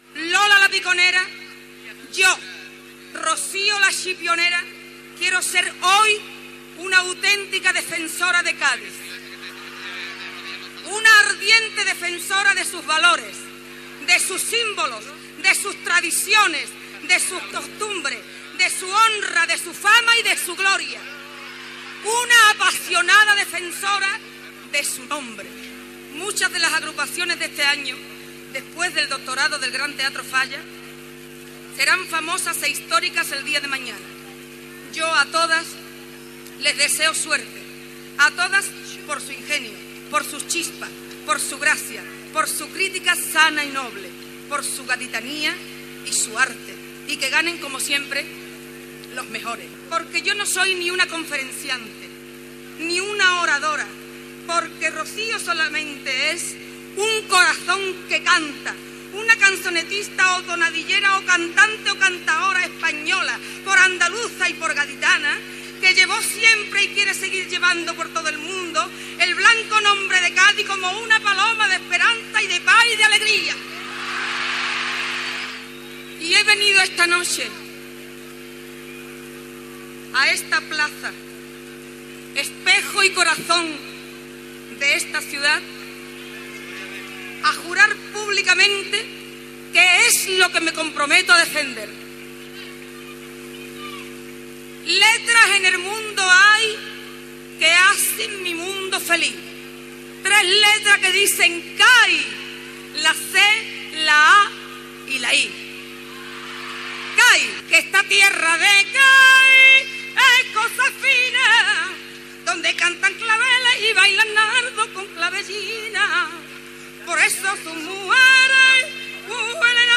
La cantant Rocío Jurado, vestiada de Lola "La Piconera", fa el pregó del Carnaval de Cadis. Va ser la primera dona que va fer el pregó d'aquella festa